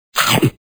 gameFail.mp3